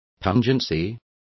Complete with pronunciation of the translation of pungency.